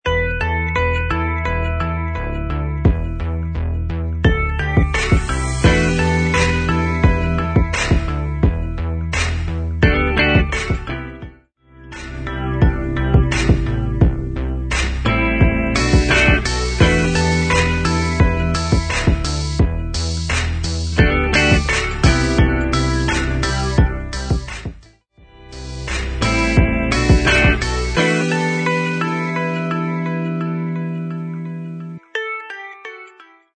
Music Beds
Dream